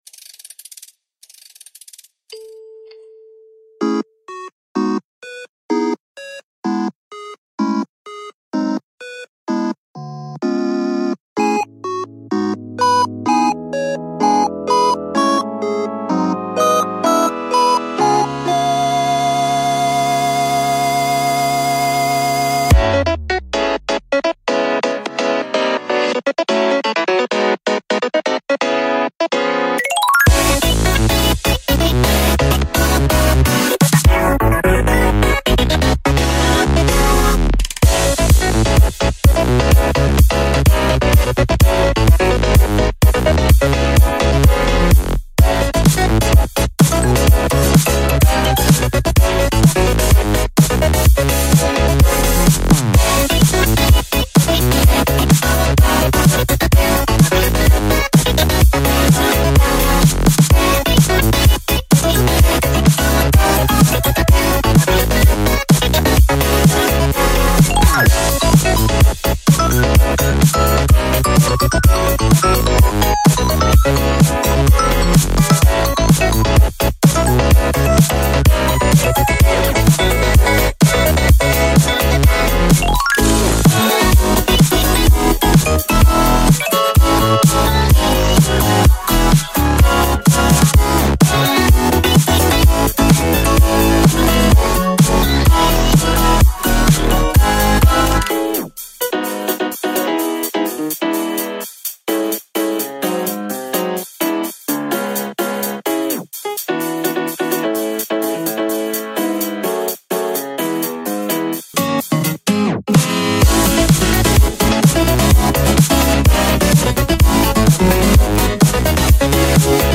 BPM127
MP3 QualityMusic Cut